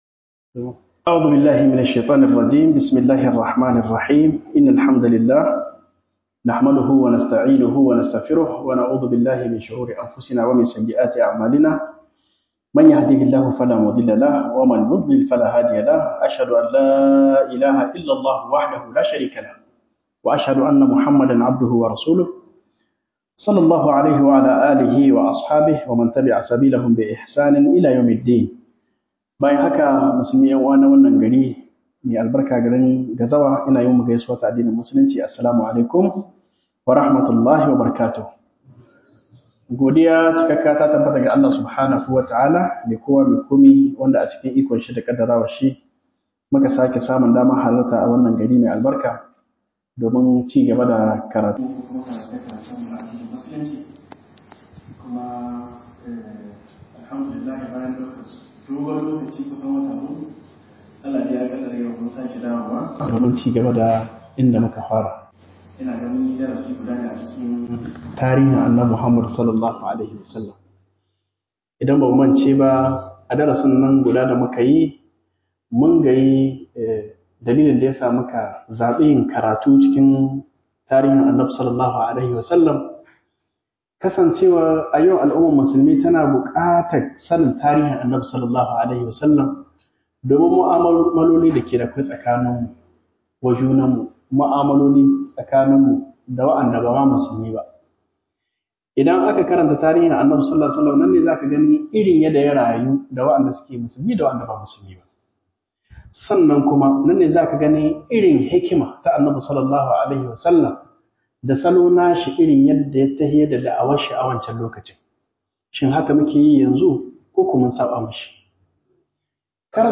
Mouhadara